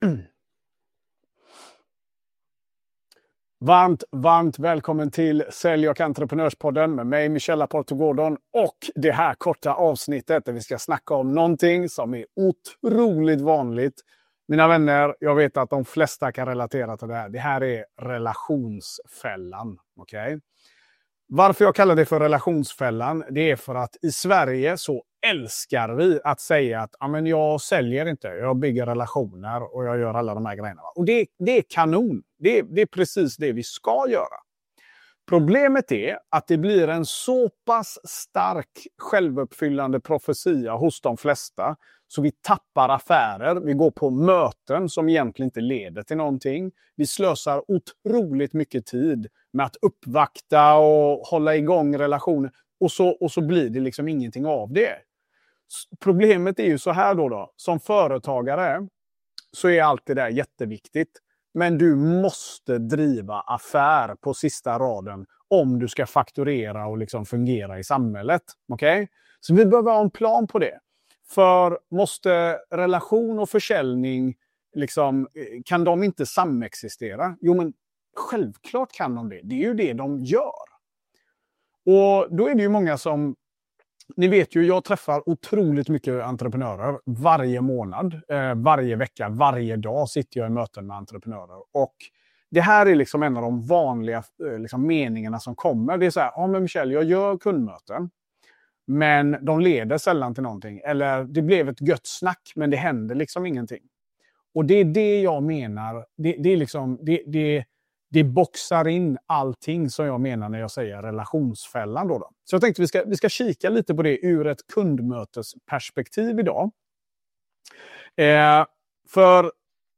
How to become relevant on a crowded market - My TEDx Talk from TEDx Malmö digital event 2020At TEDx Malmö digital event 2020 I held a Masterclass. 10min about the market and what it takes to succeed in sales and building a business that stands out and delivers.